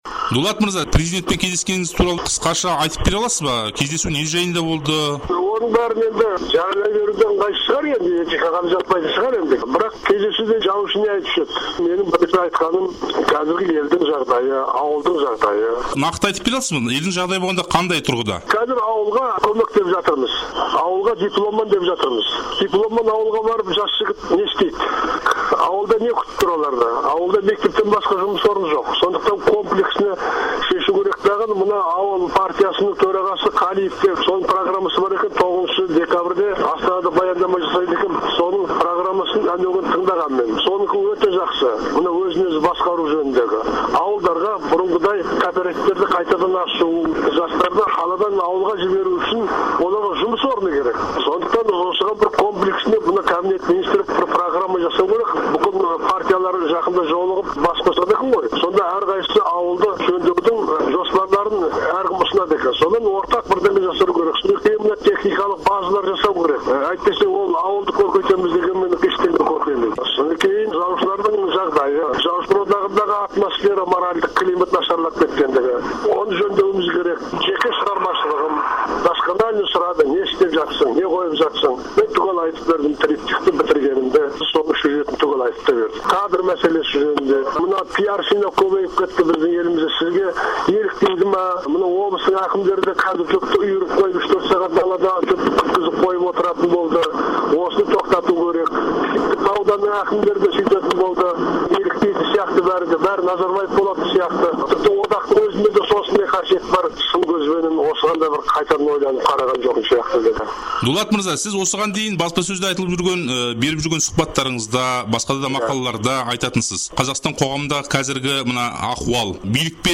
Жазушы Дулат Исабековпен сұқбат